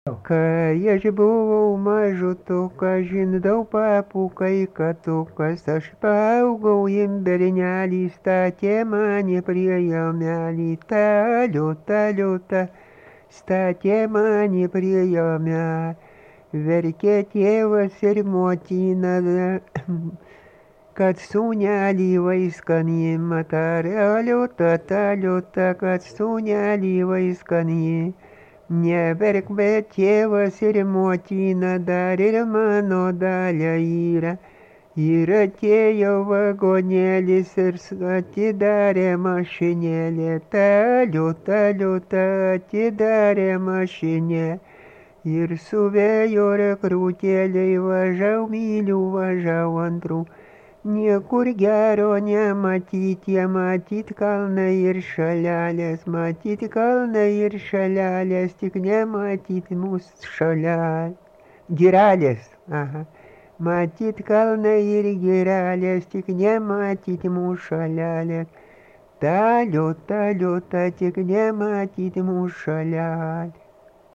Dalykas, tema daina
Erdvinė aprėptis Valančiūnai
Atlikimo pubūdis vokalinis